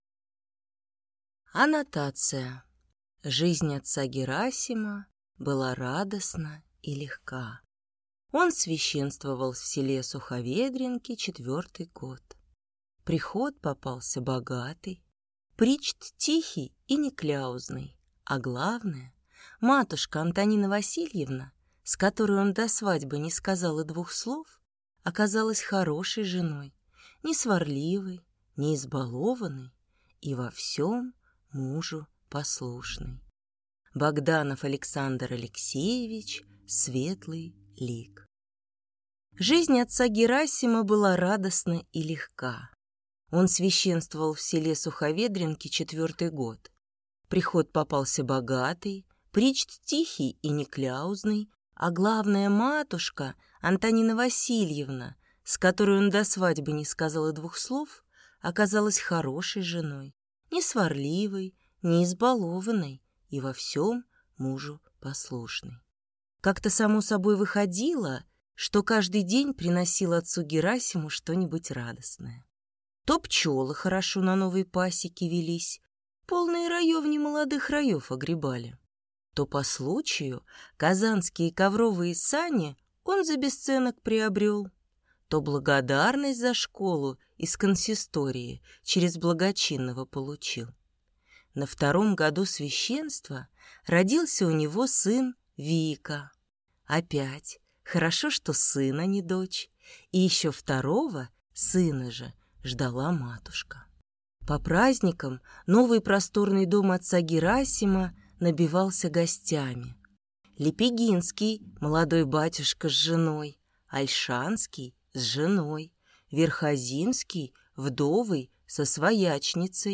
Аудиокнига Светлый лик | Библиотека аудиокниг